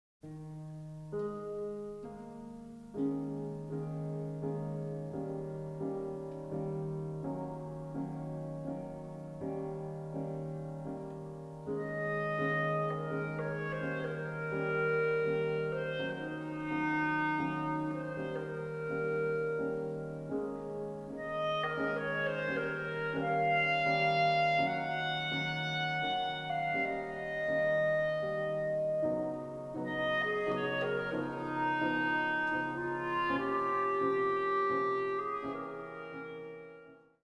has some jaunty, uplifting moments